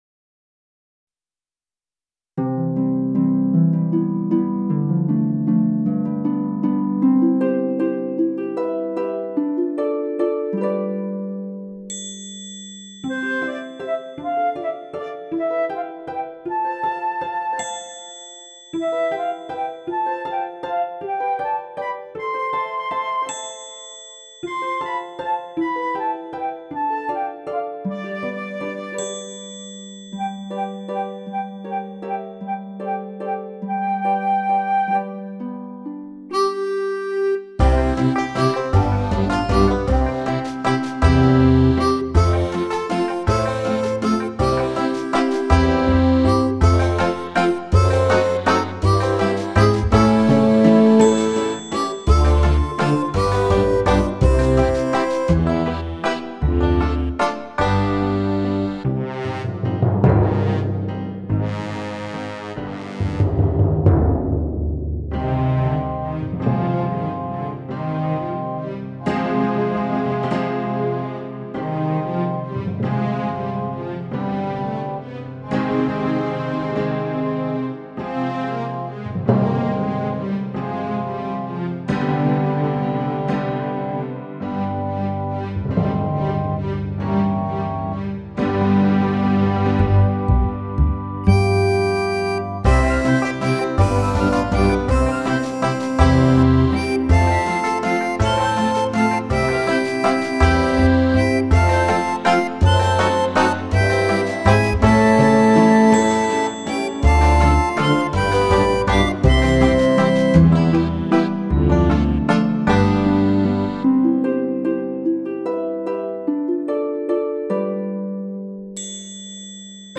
Download backing track